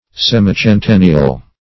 Semicentennial \Sem`i*cen*ten"ni*al\, a.